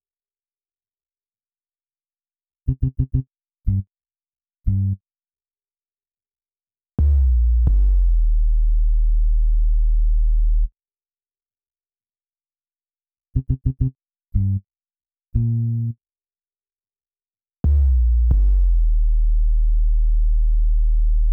H-Cruiser Bass.wav